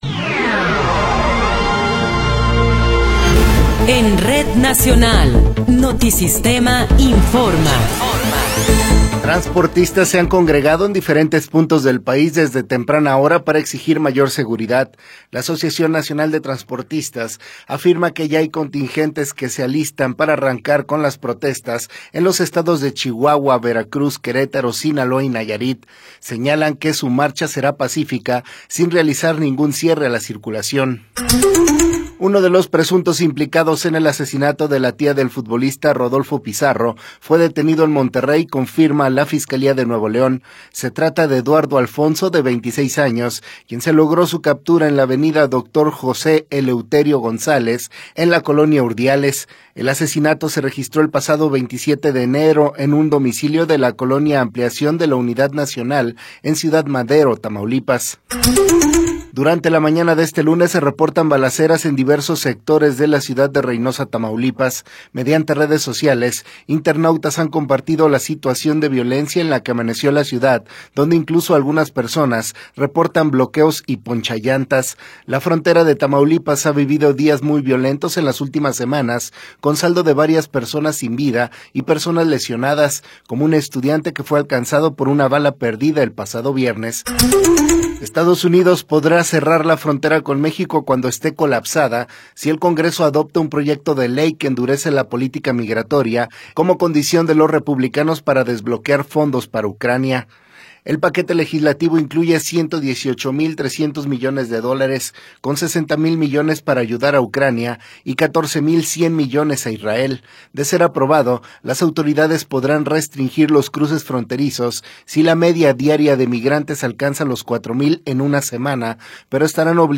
Noticiero 10 hrs. – 5 de Febrero de 2024